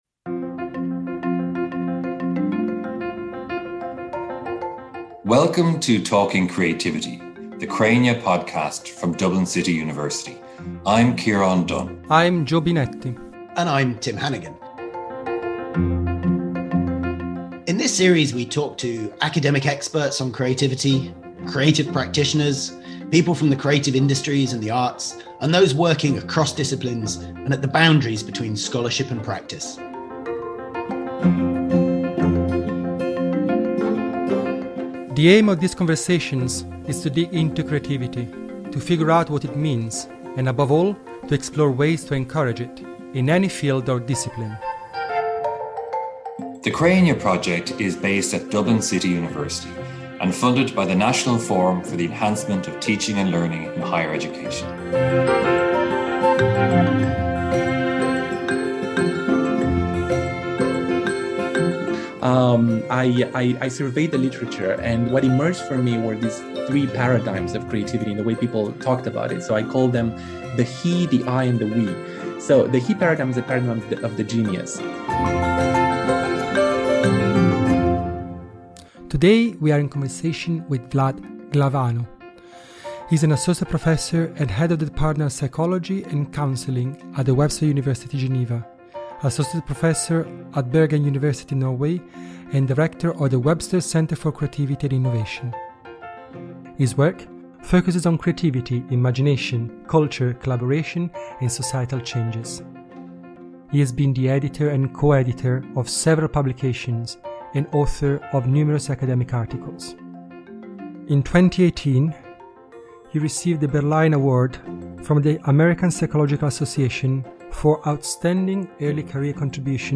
This rich and wide-ranging conversation explores the ramifications and importance of creativity in education